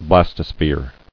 [blas·to·sphere]